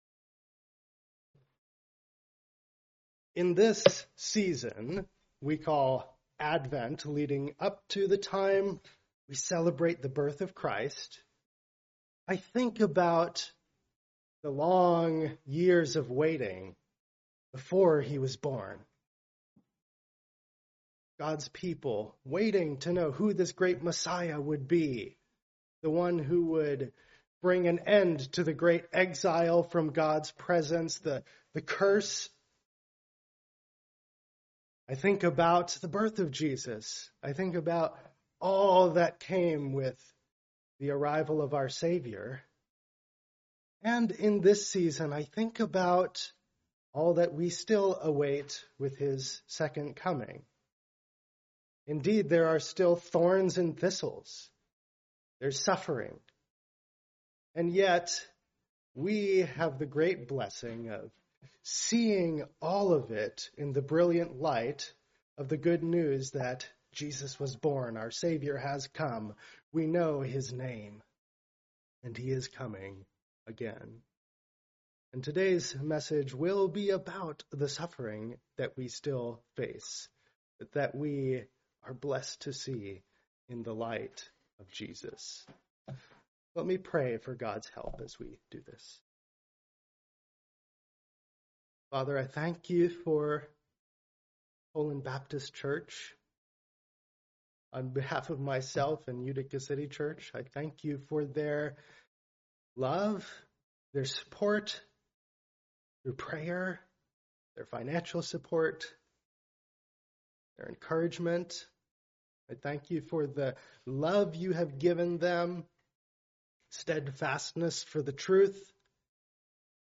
Part of the Guest Speakers series, preached at a Morning Service service.